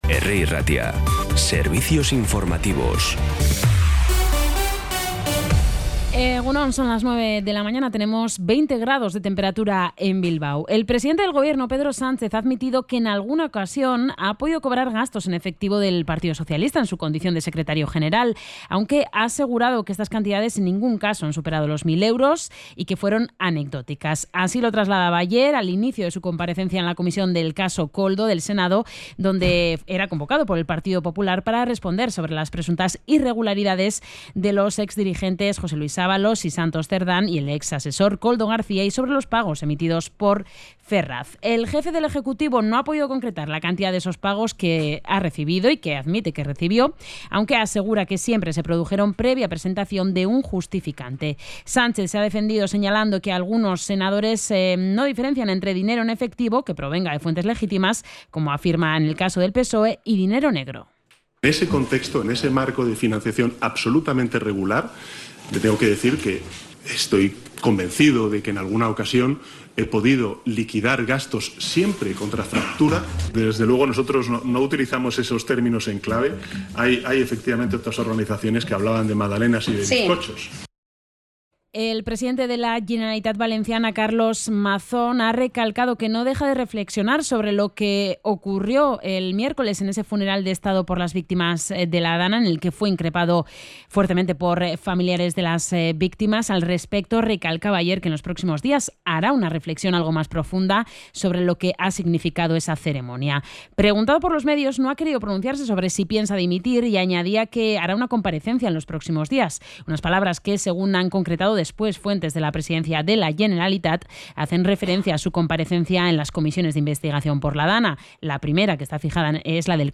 Las noticias de Bilbao y Bizkaia de las 9 , hoy 31 de octubre
Los titulares actualizados con las voces del día. Bilbao, Bizkaia, comarcas, política, sociedad, cultura, sucesos, información de servicio público.